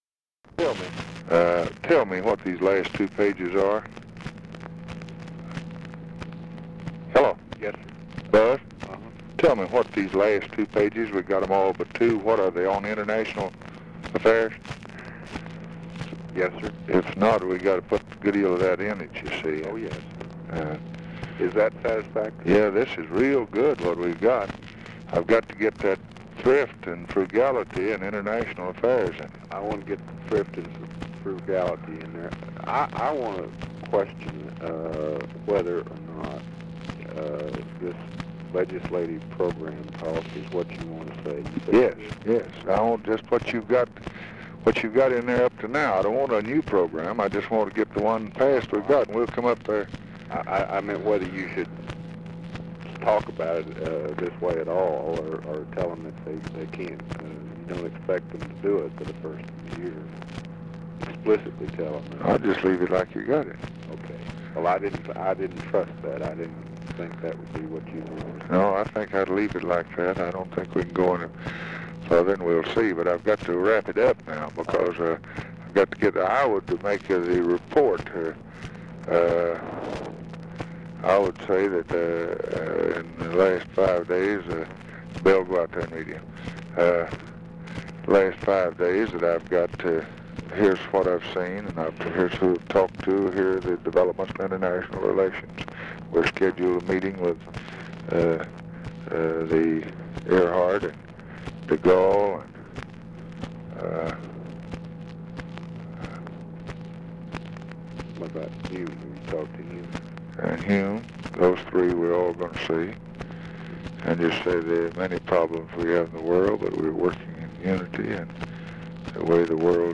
Conversation with HORACE BUSBY, November 26, 1963
Secret White House Tapes